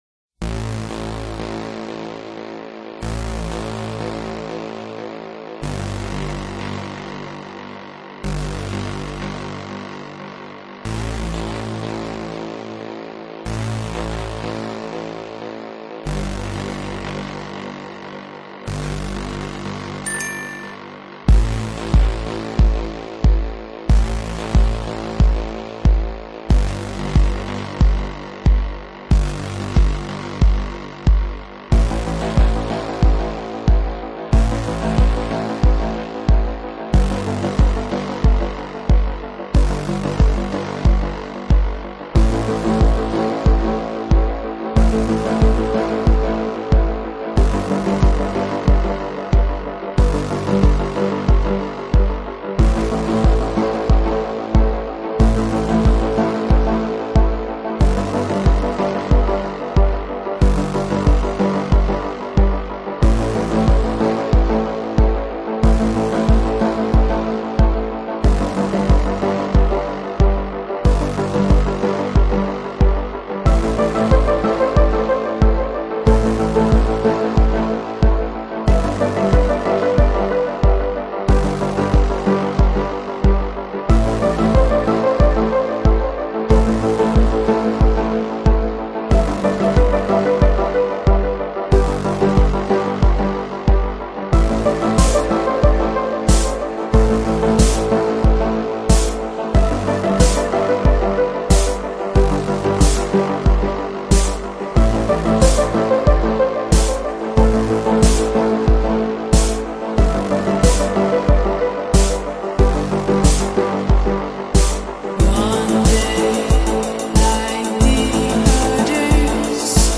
DarkPop